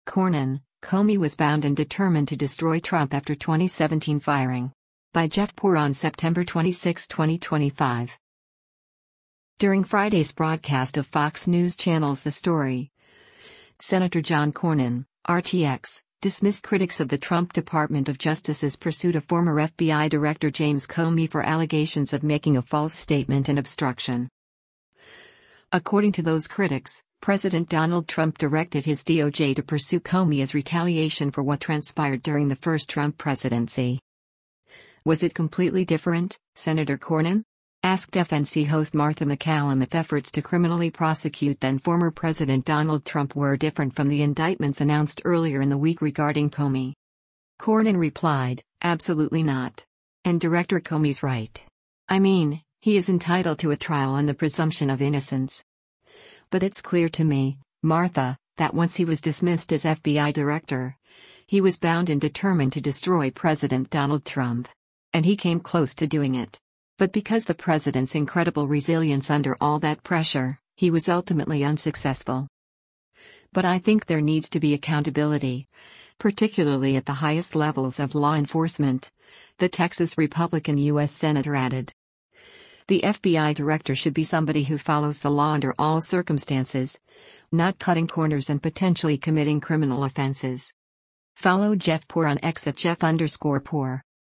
During Friday’s broadcast of Fox News Channel’s “The Story,” Sen. John Cornyn (R-TX) dismissed critics of the Trump Department of Justice’s pursuit of former FBI Director James Comey for allegations of making a false statement and obstruction.